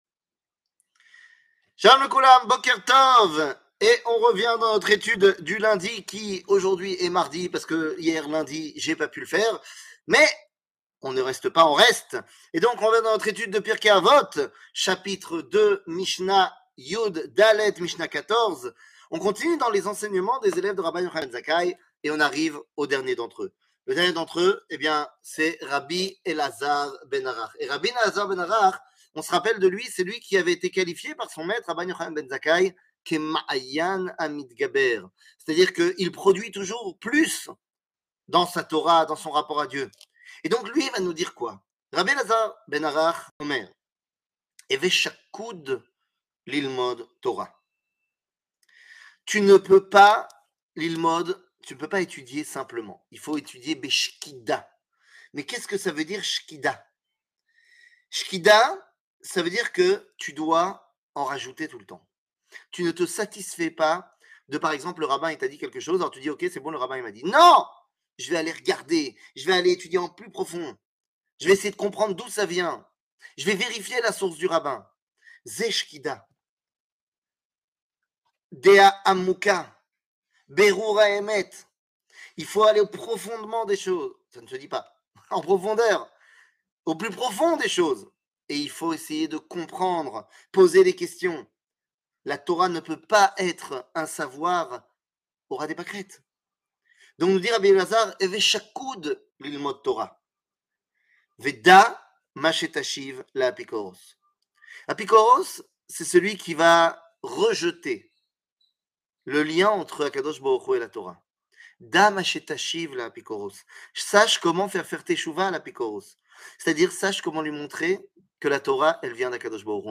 שיעורים קצרים